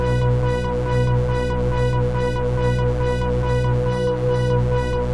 Index of /musicradar/dystopian-drone-samples/Tempo Loops/140bpm
DD_TempoDroneD_140-B.wav